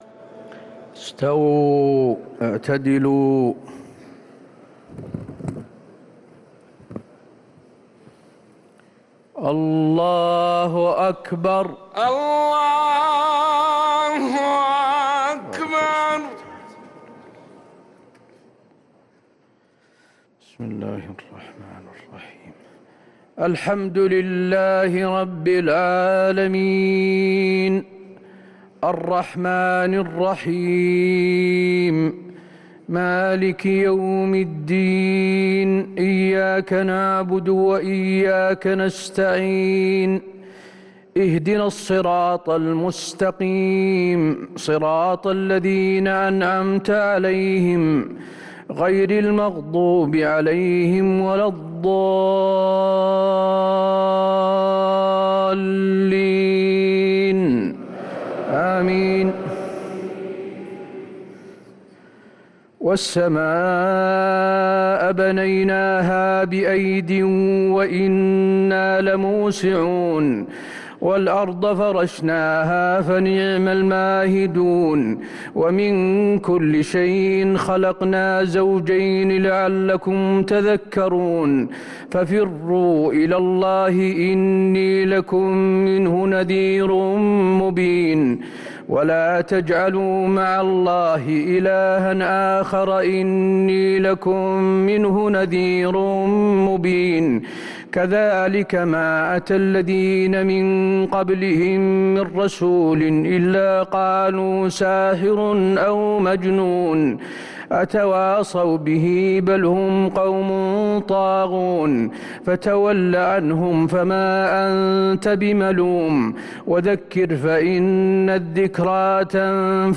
صلاة العشاء للقارئ حسين آل الشيخ 10 رمضان 1444 هـ
تِلَاوَات الْحَرَمَيْن .